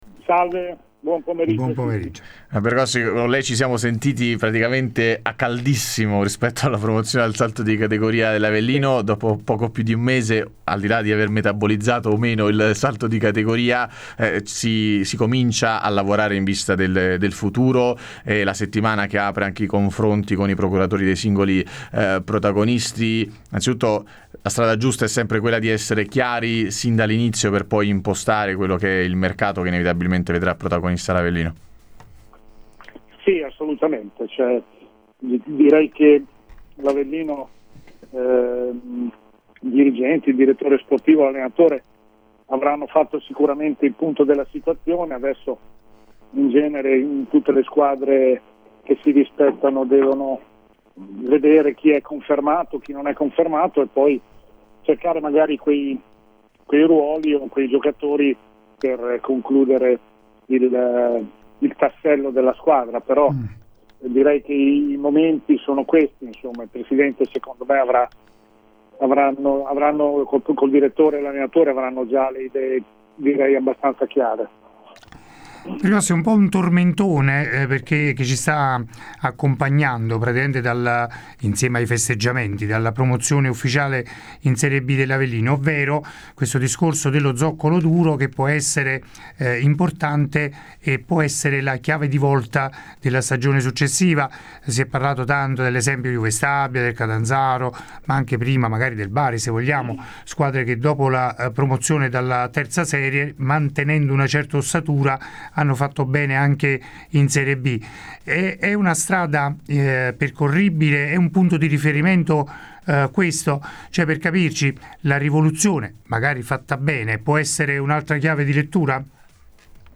Ospite di Radio Punto Nuovo, nel corso de Il Pomeriggio da Supereroi